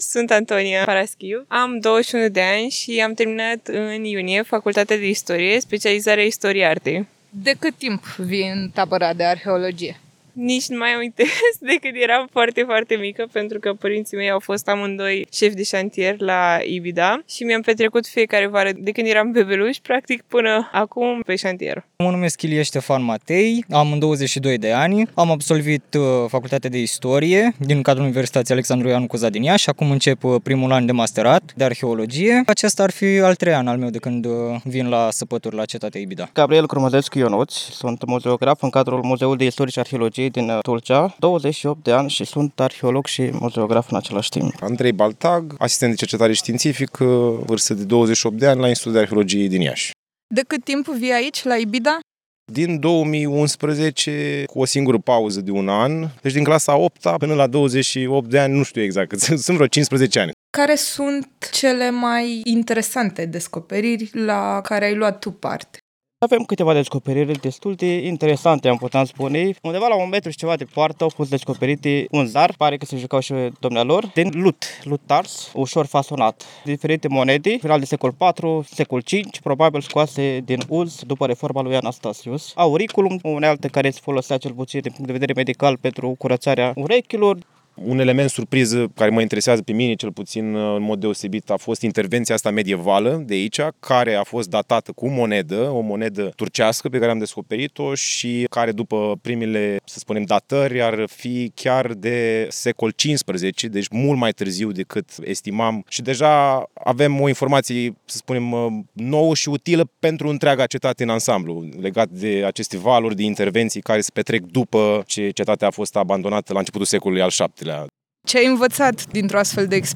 În minutele următoare, le auziți vocile,  într-un reportaj